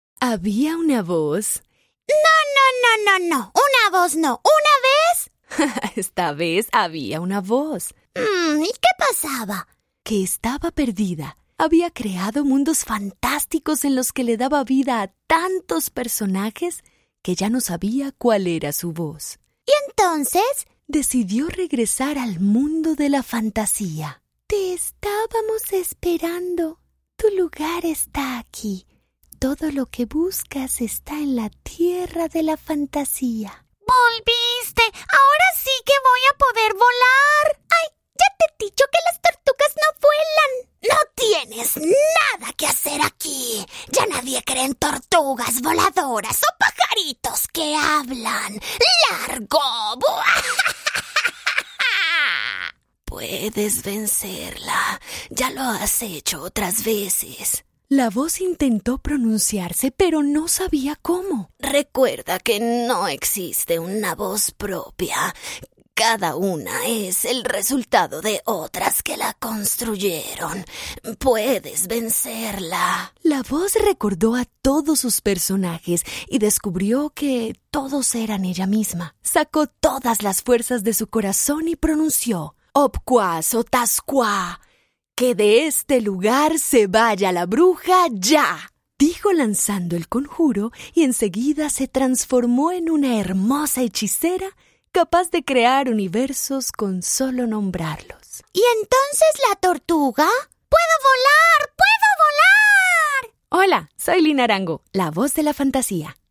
Si necesitas acento neutro latinoamericano, castellano o colombiano, esa es mi voz.
Sprechprobe: Sonstiges (Muttersprache):